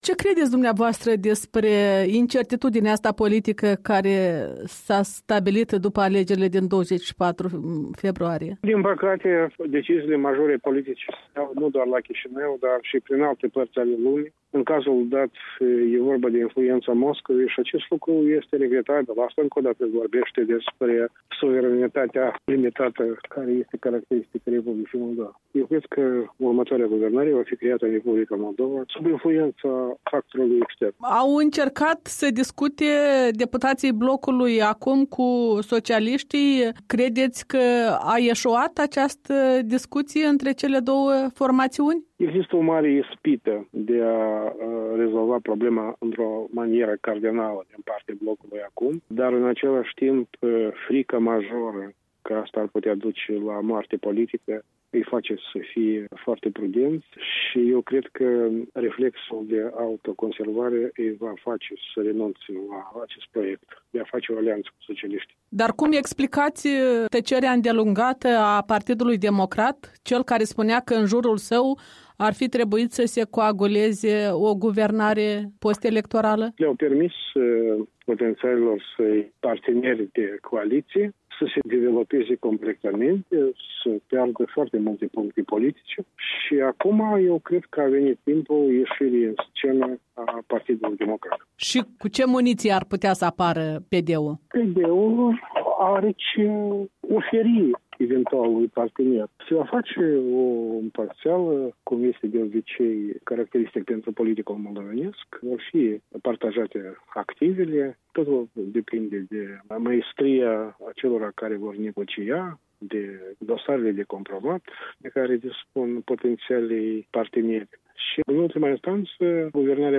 Un interviu cu comentatorul politic despre evoluția încurcatei situații post-electorale.